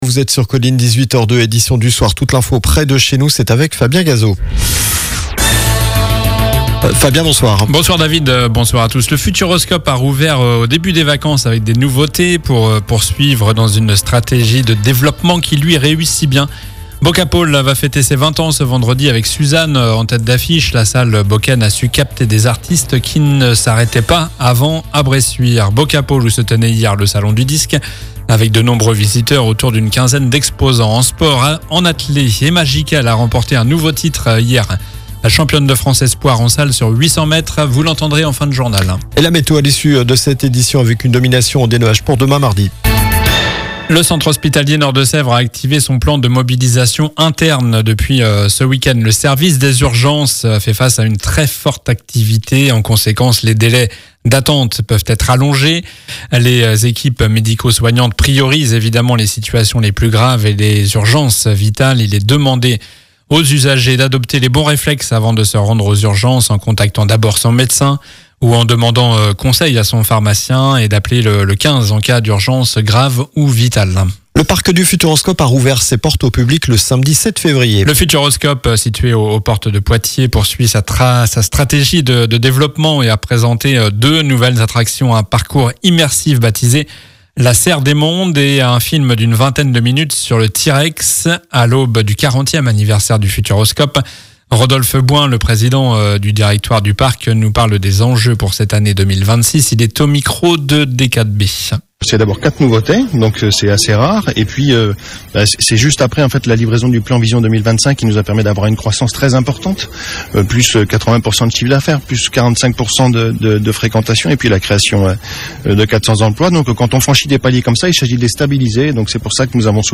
Journal du lundi 16 février (soir)